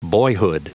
Prononciation du mot boyhood en anglais (fichier audio)
Prononciation du mot : boyhood